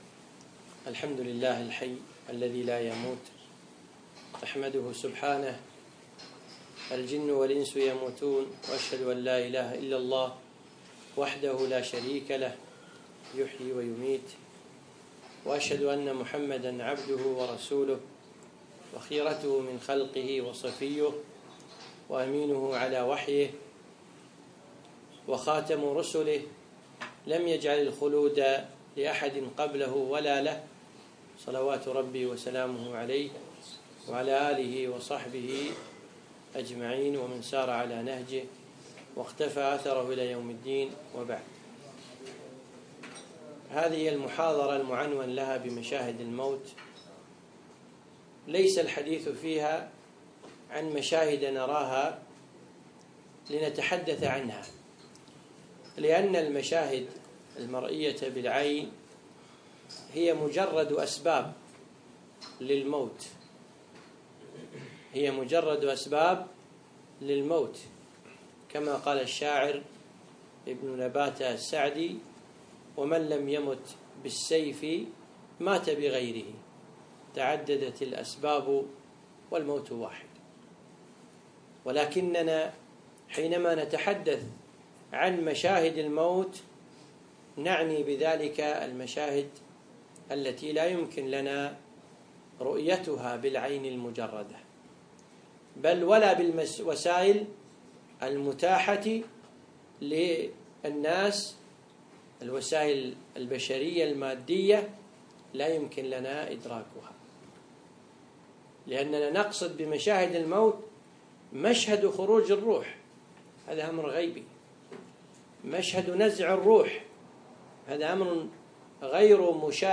يوم الأحد 25 جمادى الأخر 1437 الموافق 3 4 2016 في ديوانية شباب صباح السالم